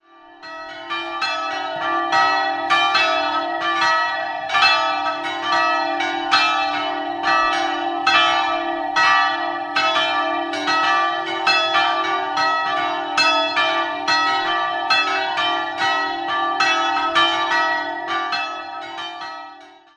Jahrhundert. 3-stimmiges Paternoster-Geläut: d''-e''-fis'' Die mittlere Glocke wurde 1774 von Kissner in Stadtamhof gegossen.